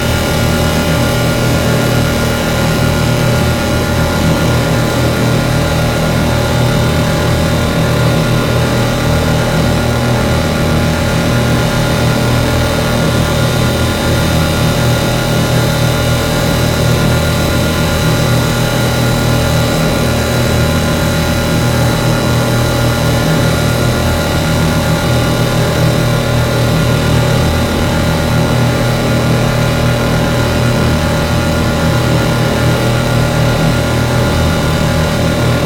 TAPE SPLIT
RADIO AND SYNTHETIC ELECTRICAL ARTIFACTS
RECORDED IN TANZANIA AND USA